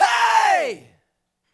Track 11 - Vocal Hey 02.wav